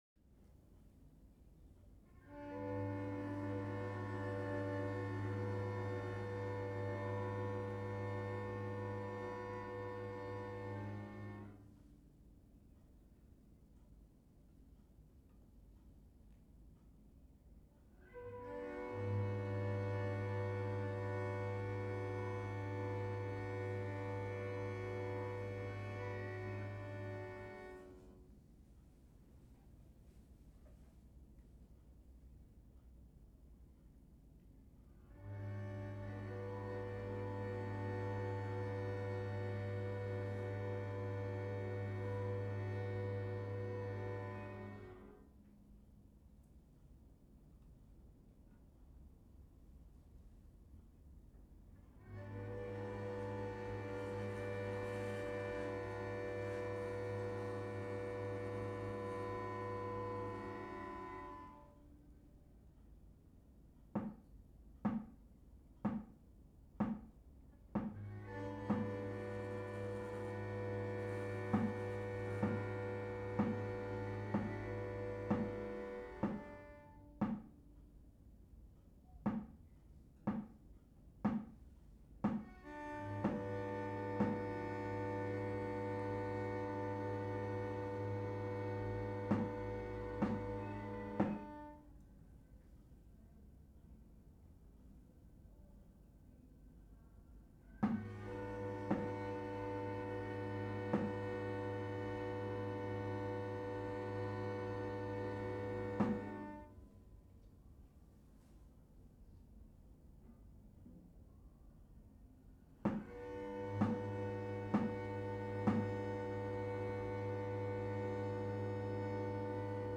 environmental sounds in Bristol
His 47 insignificant variable sounds with one drummer drumming involves sustained blocks of ensemble sound with subtle variation and a lone drummer, separated by measured silences. It’s a great piece in itself, but after a few minutes it became clear that there was a lot of environmental sound outside which was intruding. Initially the relatively loud dance music was a little diverting, becoming audible in the silences only to be covered by the musicians.